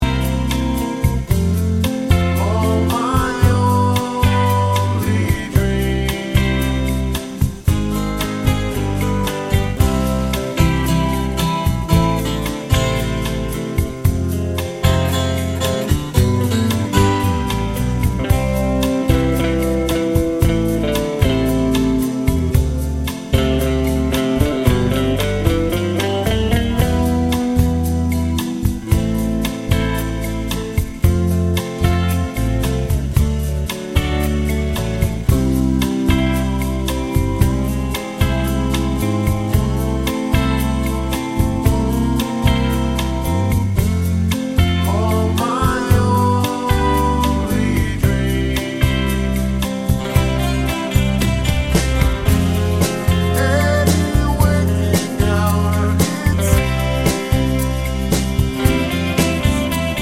Down 2 Semitones Soundtracks 2:52 Buy £1.50